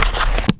pr_tome_close.wav